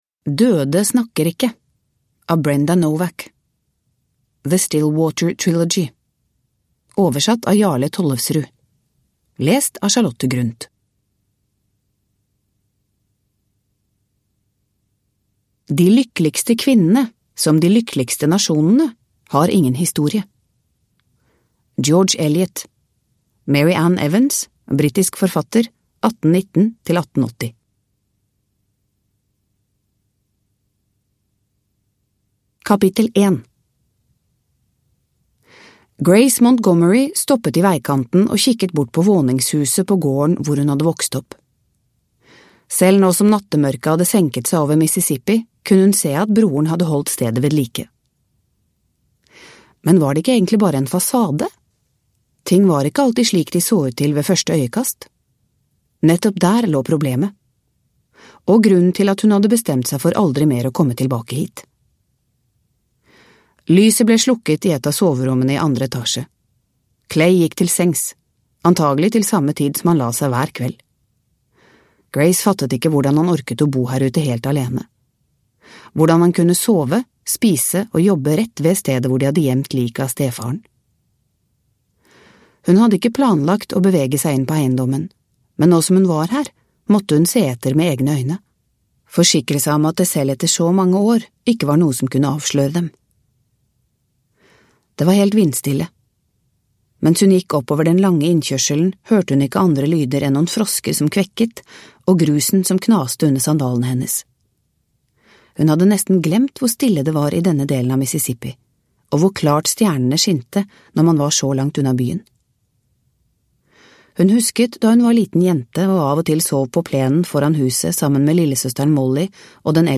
Døde snakker ikke – Ljudbok – Laddas ner
Produkttyp: Digitala böcker